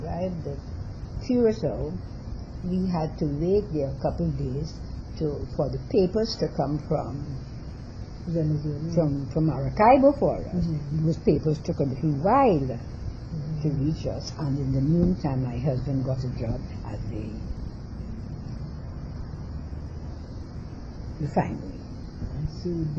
dc.description2 audio cassettesen
dc.typeRecording, oralen